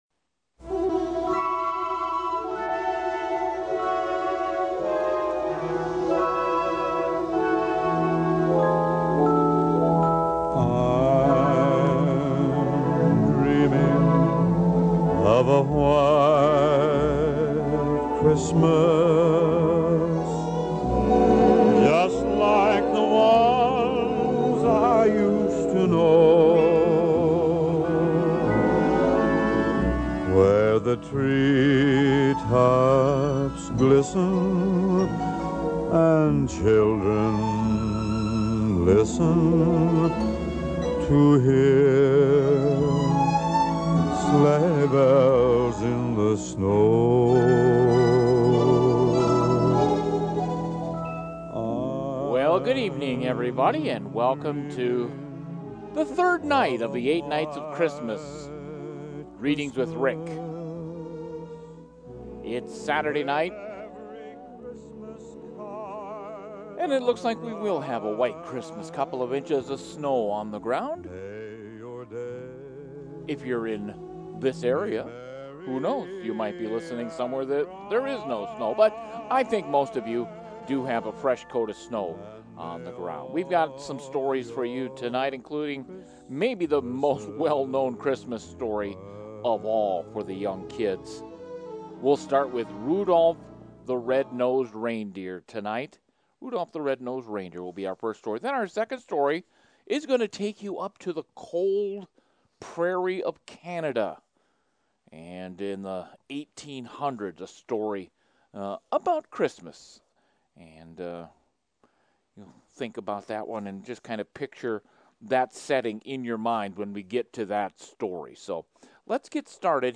In the third episode of the 8 Nights of Christmas, children can enjoy listening to the re-telling of the classic 'Rudolph the Red-Nosed Reindeer".